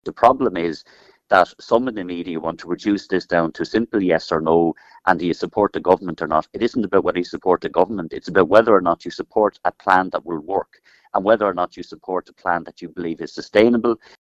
But Sinn Fein’s health spokesperson, David Cullinane, believes it’s rushed legislation that won’t work……